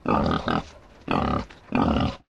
flesh_idle_1.ogg